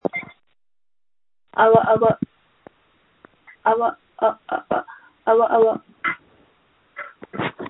• When you call, we record you making sounds. Hopefully screaming.
• This website is an archive of the recordings we received from hundreds of thousands of callers.